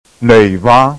***其实，苏州话欧韵母发音时候的嘴形，要比普通话中”ei”韵母的圆，而且基本接近圆形了。你再试试看～～
正确的版本